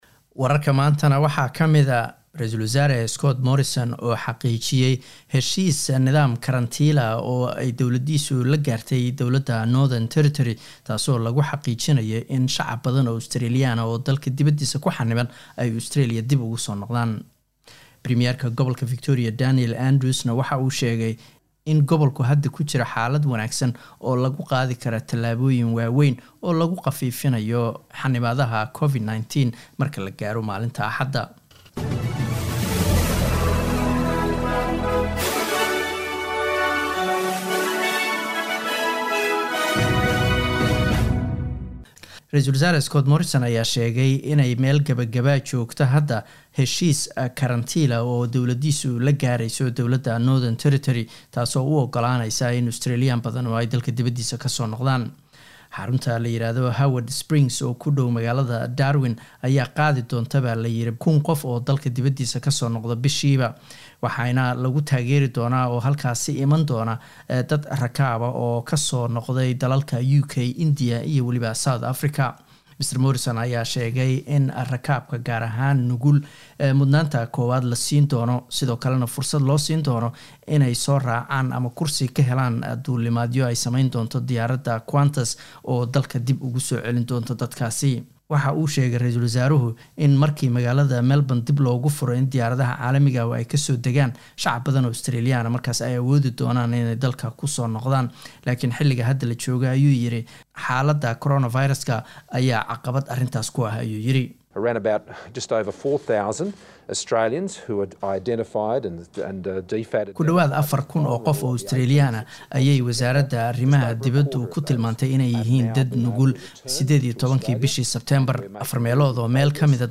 Wararka SBS Somali Jimco 16 October